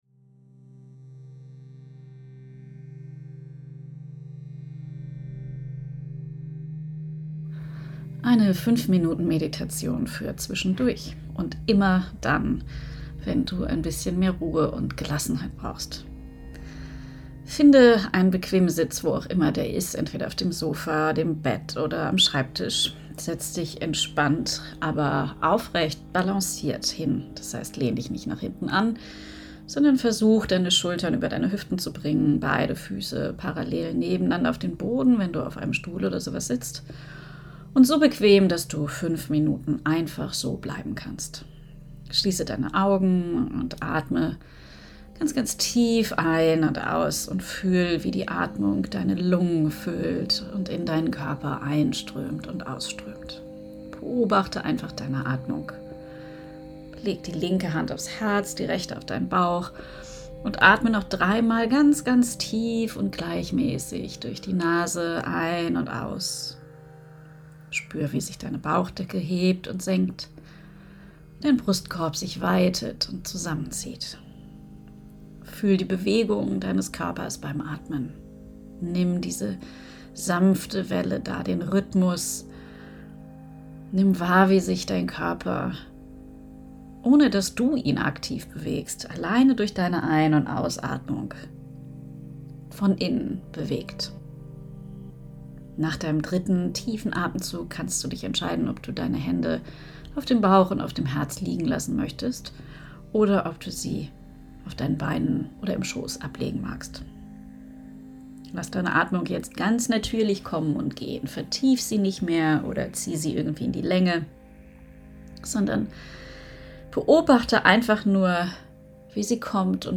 5-Minuten-Meditation-fuer-innere-Ruhe.mp3